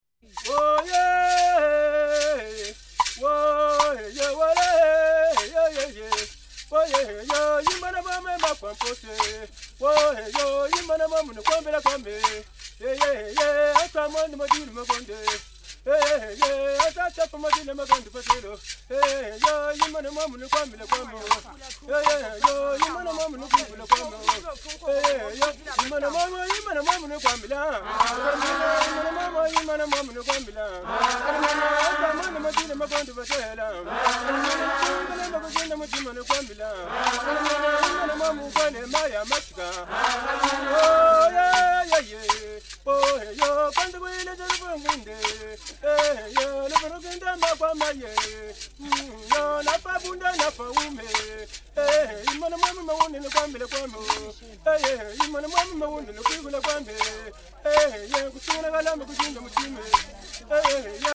Rhythm stick
As regards sound, a clear and high pitch is preferred, supplementing the rest of the percussion, or to provide a rhythmical pulse for group singing. Generally the pattern is the same throughout the entire song and sometimes it is no more than a beat on each count.
This type of instrument is used in recordings of our sound archives made with the Congolese peoples mentioned hereafter where it appears with the following vernacular names: